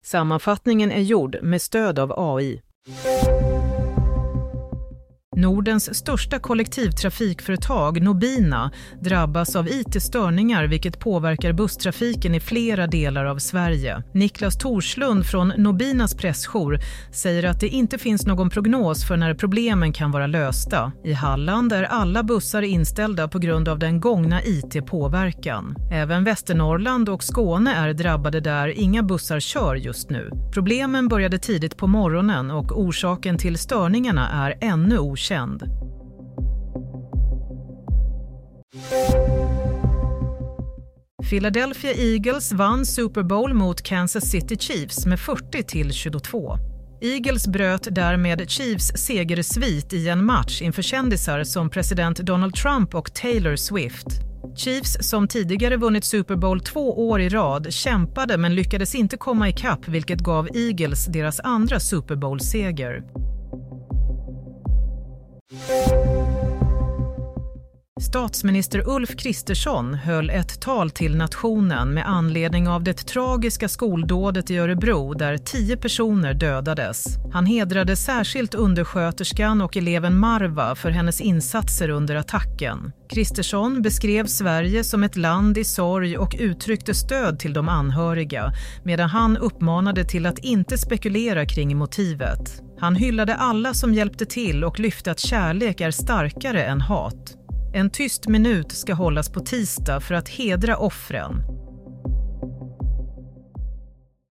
Nyhetssammanfattning - 10 februari 07.40
Sammanfattningen av följande nyheter är gjord med stöd av AI.